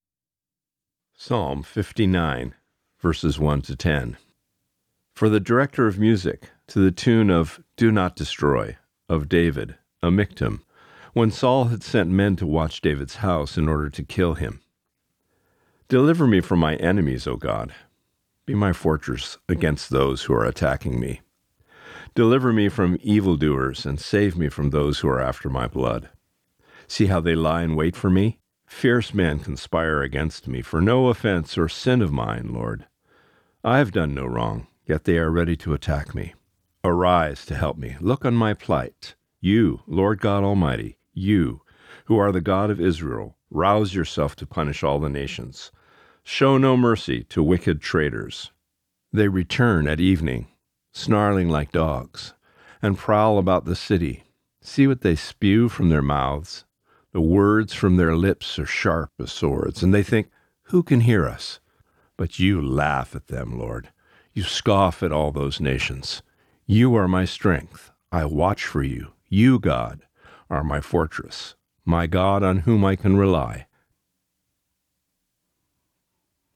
Reading:  Psalm 59:1-10 (NIV)*